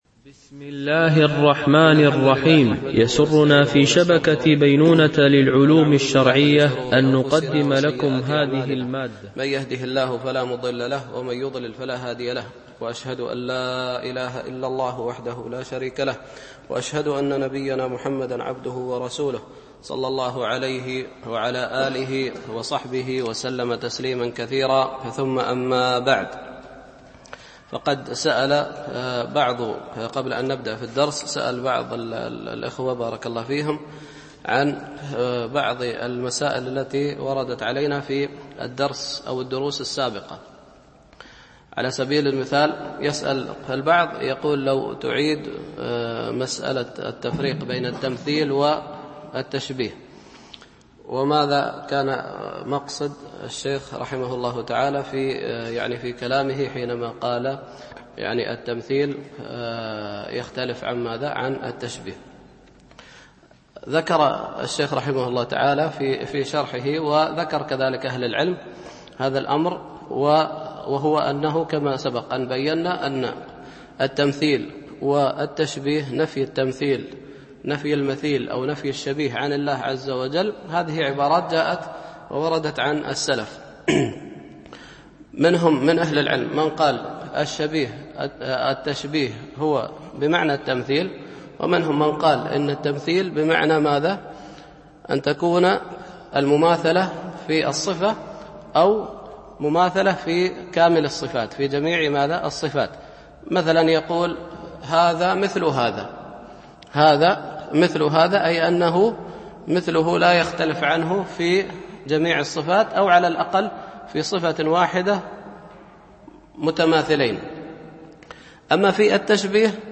شرح القواعد المثلى في صفات الله وأسمائه الحسنى ـ الدرس 12 (قواعد في صفات الله - القاعدة 7)